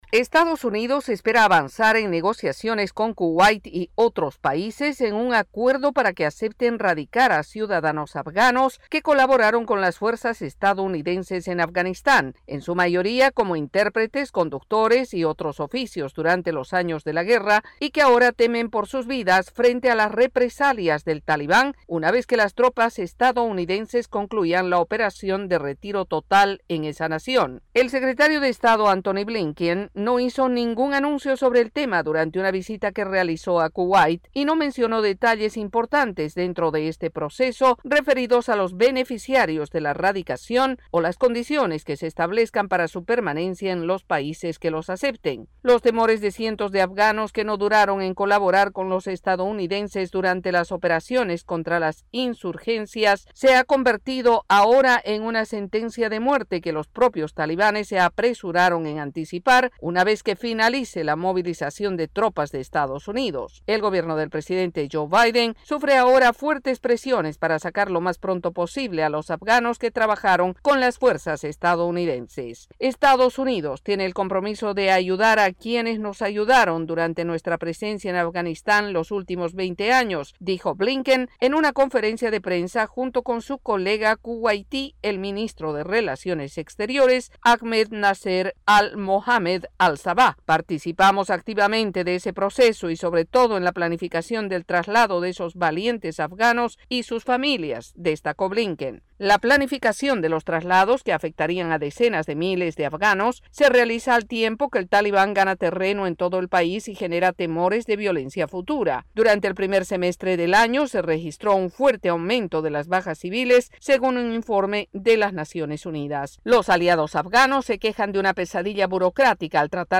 El Gobierno de Estados Unidos adelanta esfuerzos para lograr un país que acoja a cientos de afganos que colaboraron con las tropas estadounidenses de Afganistán. El informe